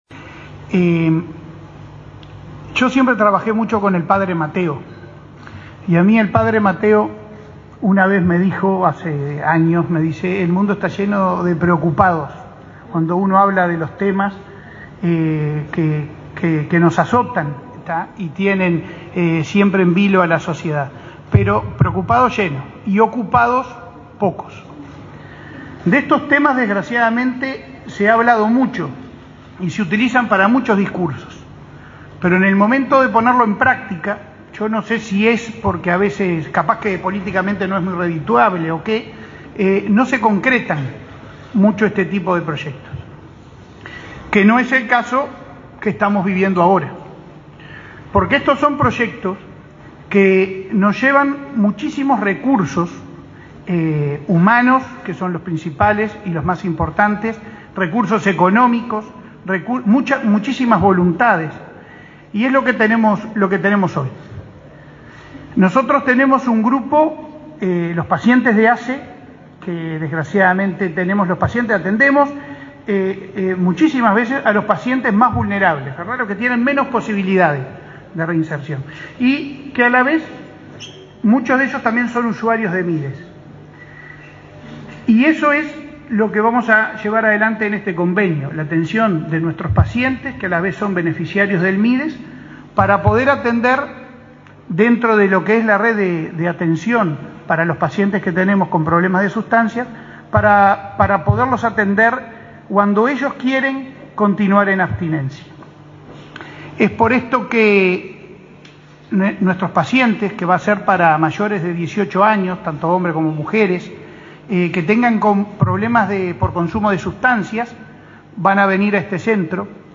Palabras del presidente de ASSE, Leonardo Cipriani
Palabras del presidente de ASSE, Leonardo Cipriani 04/04/2022 Compartir Facebook X Copiar enlace WhatsApp LinkedIn El presidente de la Administración de los Servicios de Salud del Estado (ASSE), Leonardo Cipriani, participó este lunes 4 en Montevideo, junto al ministro de Desarrollo Social, Martín Lema, en la inauguración de dos centros diurnos para personas que padecen consumo problemático de drogas.